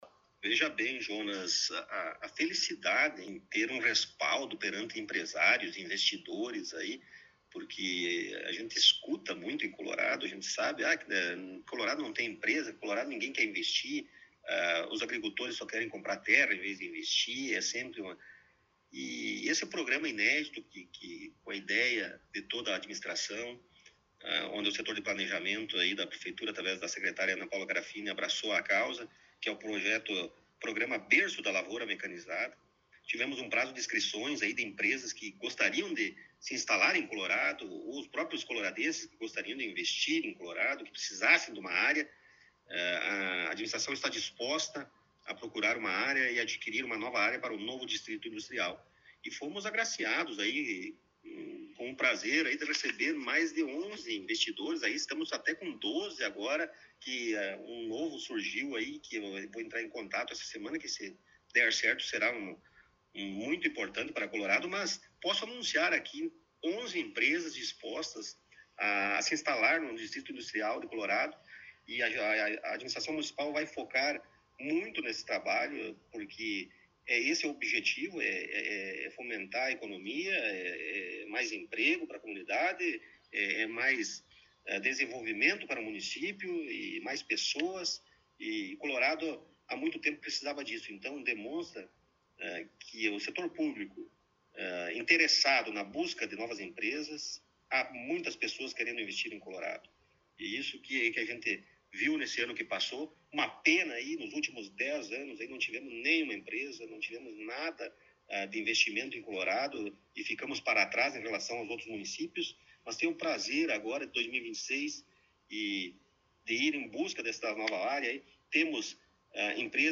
No início deste novo ano, tivemos a oportunidade de entrevistar o prefeito Rodrigo Sartori em seu gabinete na Prefeitura Municipal.